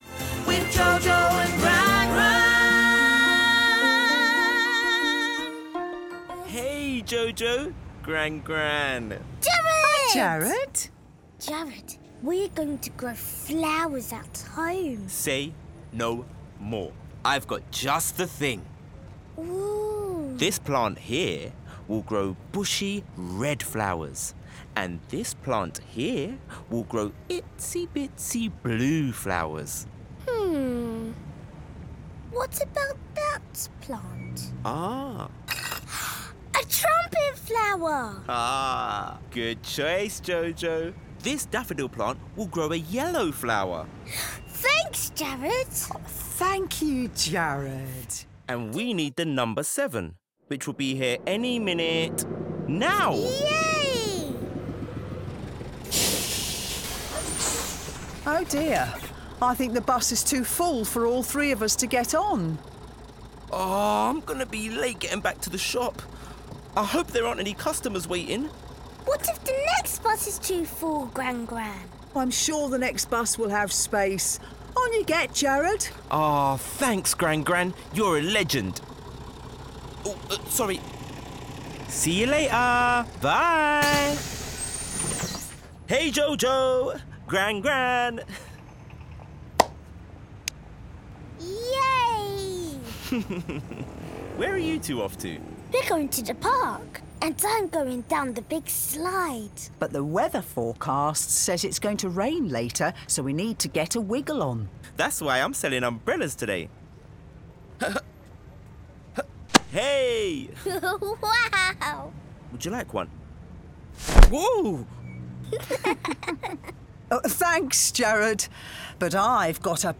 Cool, clear and confident.
• Male
Jojo and Gran Gran animation audio